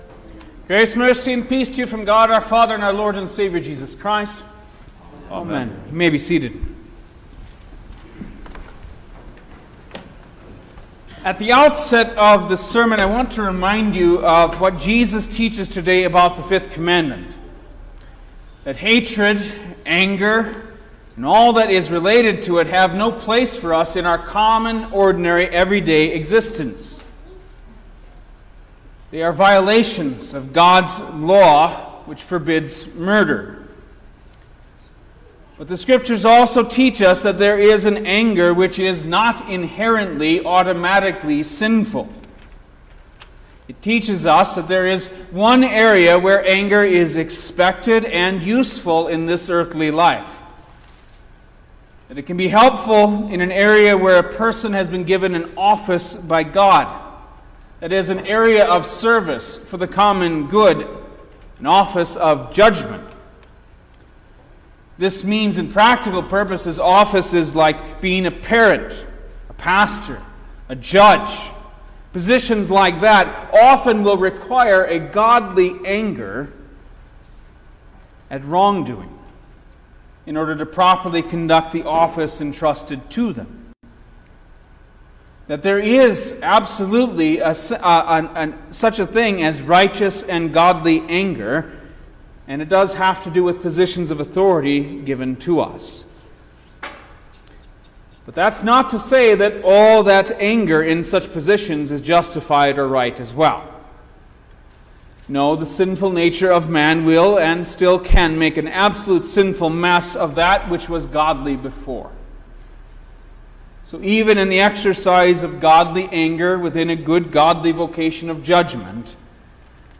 July-7_2024_Sixth-Sunday-after-Trinity_Sermon-Stereo.mp3